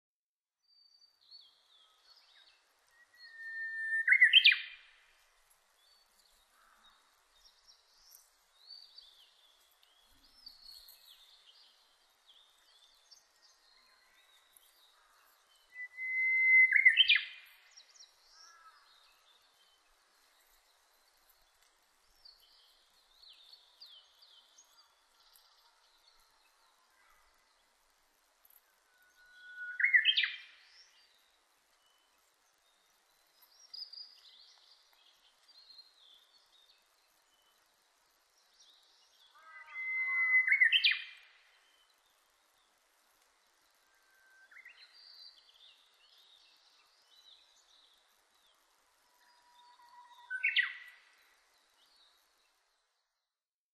ウグイス　Cettia diphoneウグイス科
日光市稲荷川中流　alt=730m  HiFi --------------
Mic.: Sound Professionals SP-TFB-2  Binaural Souce
他の自然音：　 オオルリ・センダイムシクイ・ハシブトガラス